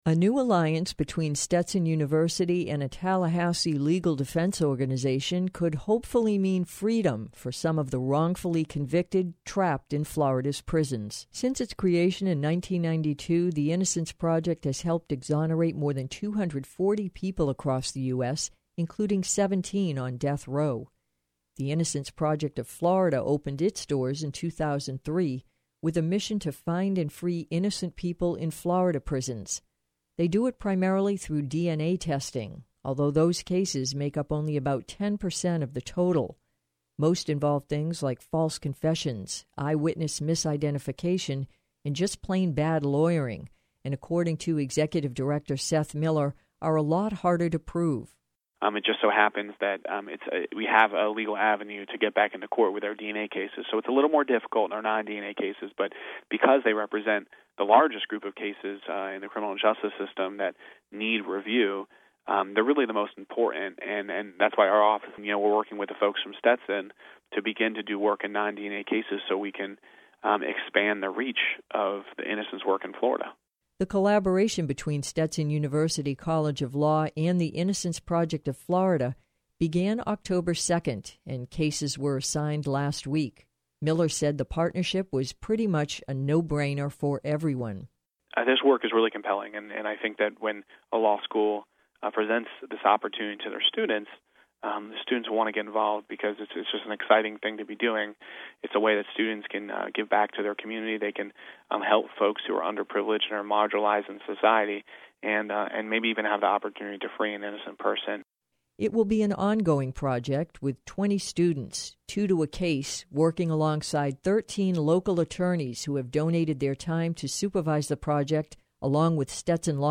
WMNF Radio Evening News November 2009